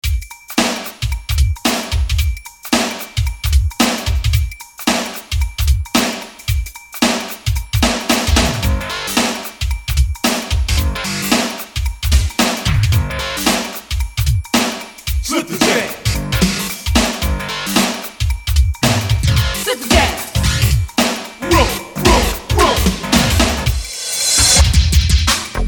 • Качество: 234, Stereo
саундтрек